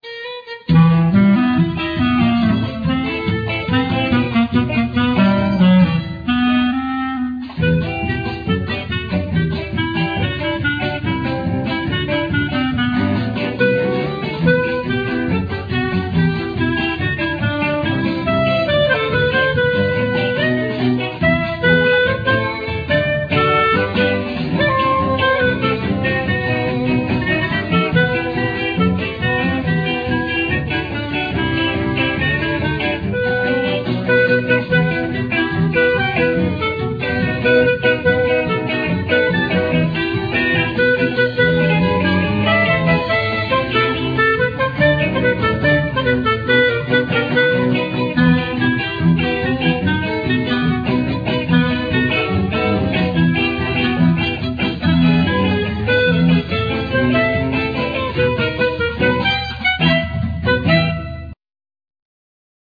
Clarinett
Violin
Elctric guitar
Cello
Percussions
Accordian